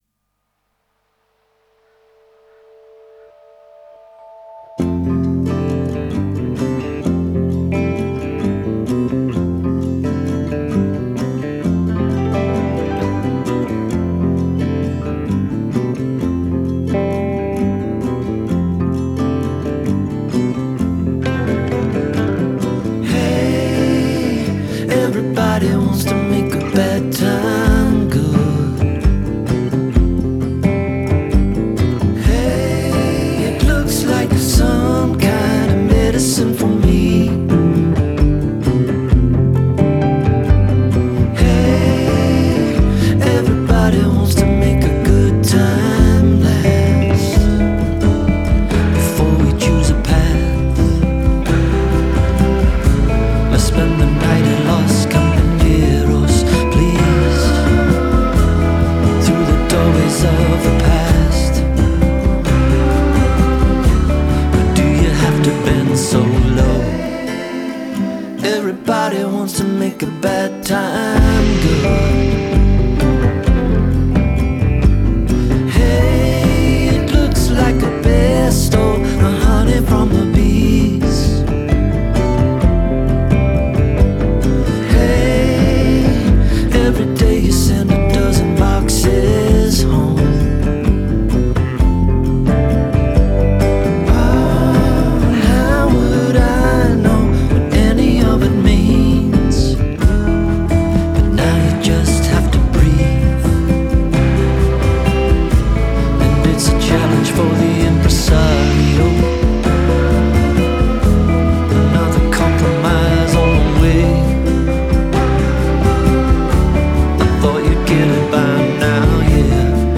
What they do go for though is control and restraint.